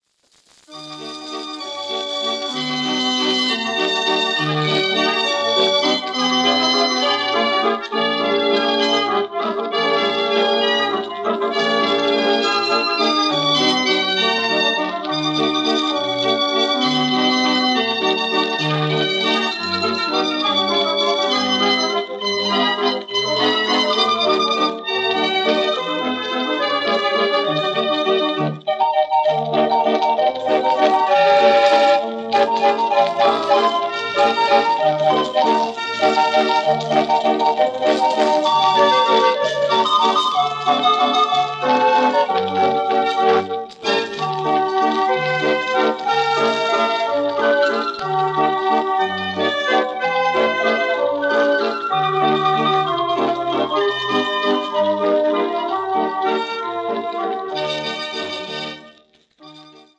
Formaat 78 toerenplaat, 10 inch